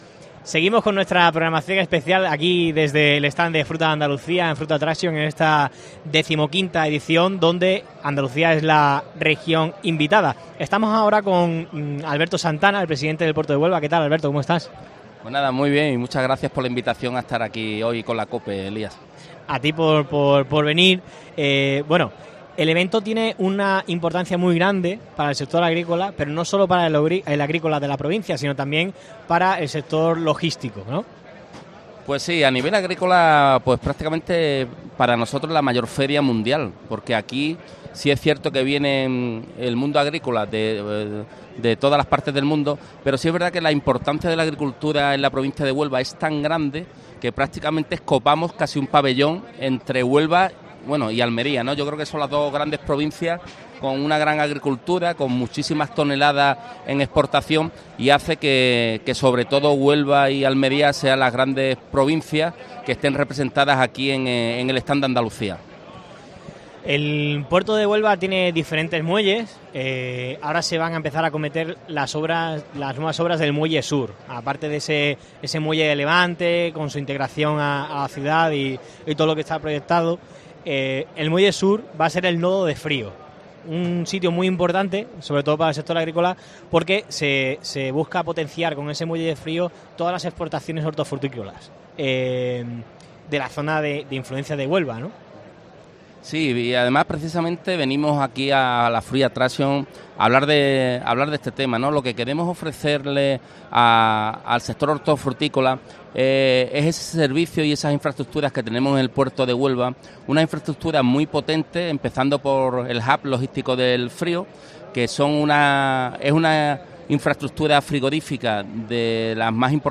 Fruit Attraction 2023 | Entrevista a Alberto Santana, presidente del Puerto de Huelva